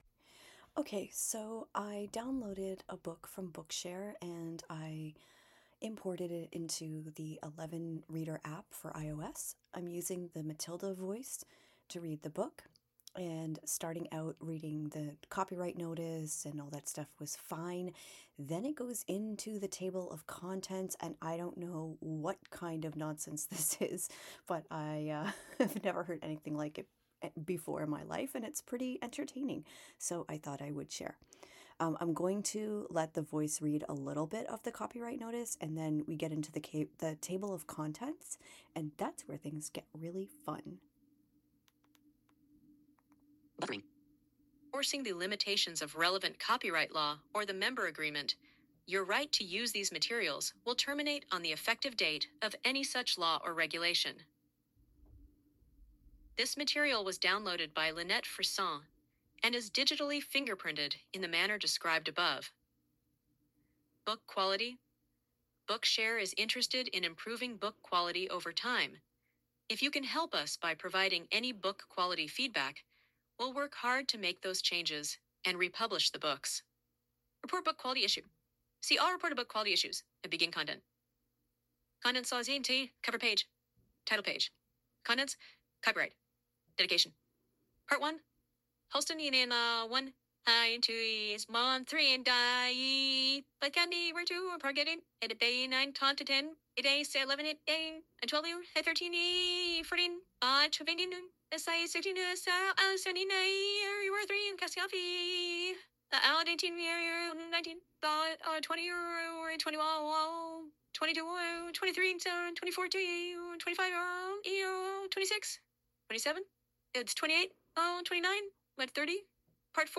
Good morning! Here, for your listening pleasure, is an AI Eleven Labs voice run amuck...or something. I loaded a book into Eleven Readeron iOS yesterday.
As soon as the voice starts to read the table of contents, it goes whacky.